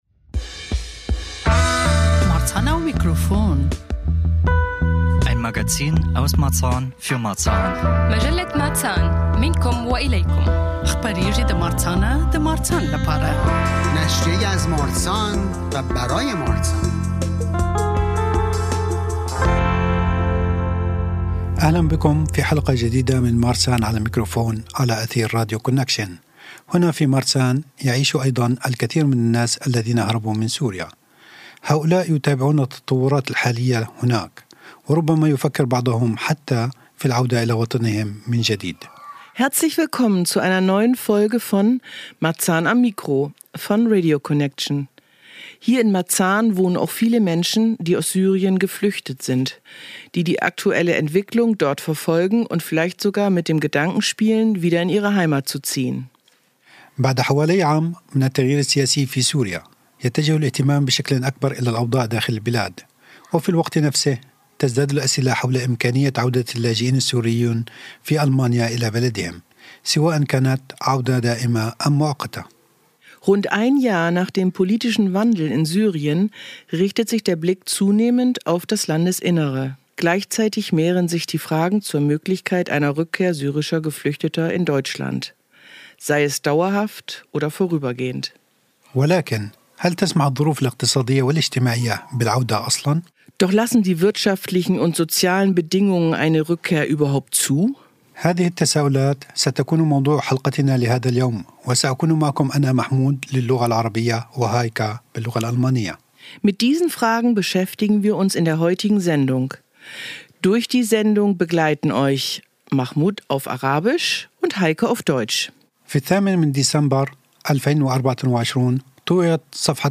Außerdem spricht er mit einem Experten darüber welche Bedingungen in Syrien erfüllt sein müssen, damit Geflüchtete zurückkehren können.